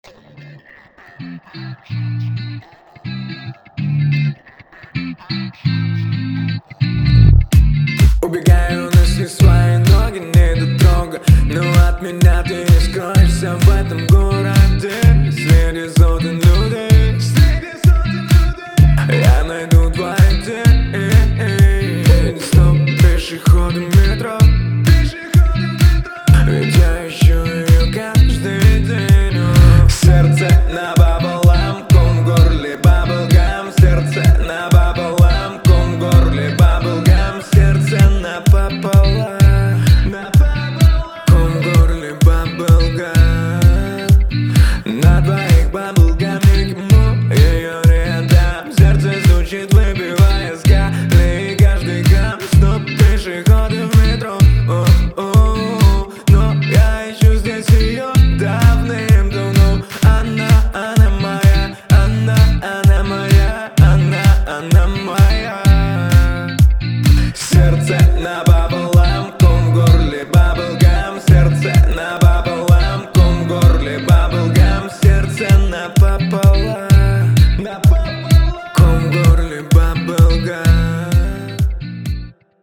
яркая и энергичная песня